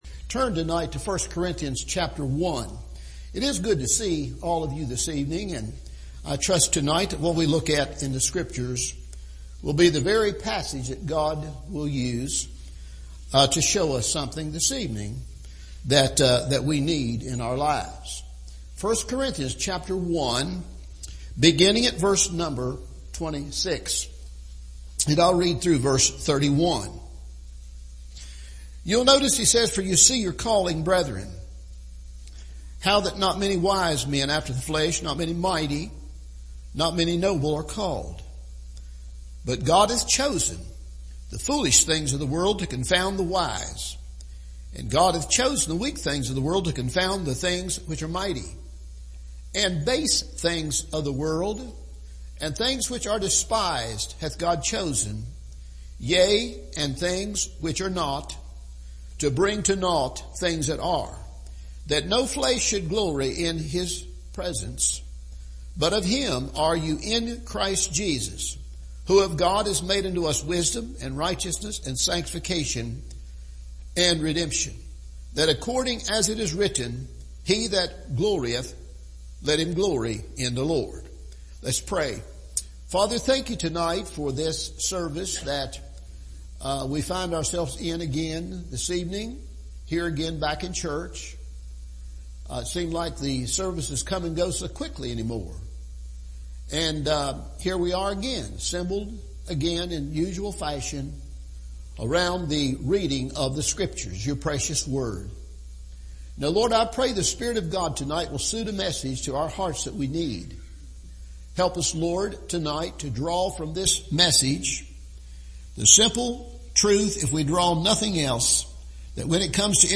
What Do We Have to Brag About? – Evening Service